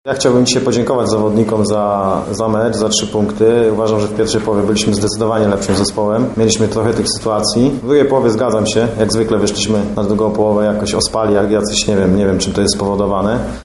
Oto co powiedzieli podczas pomeczowej konferencji prasowej przedstawiciele obu ekip: